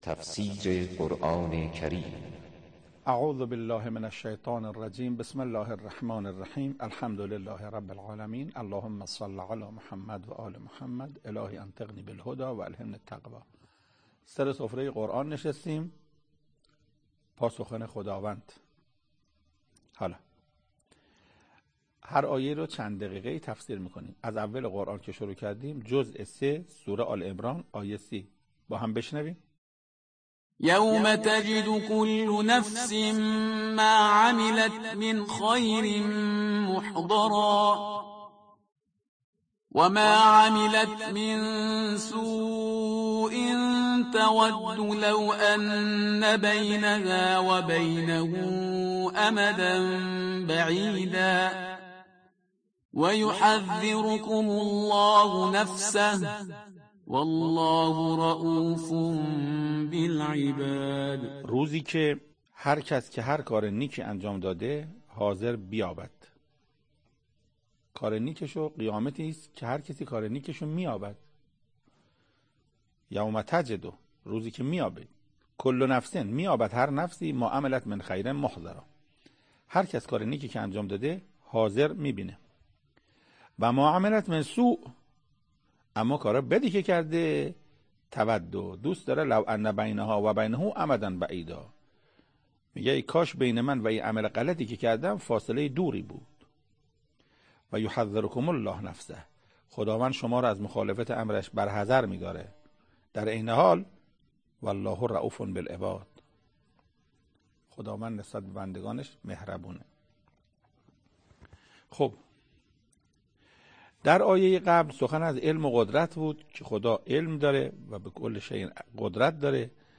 تفسیر آیه سی ام سوره مبارکه آل عمران توسط حجت الاسلام استاد محسن قرائتی به مدت 8 دقیقه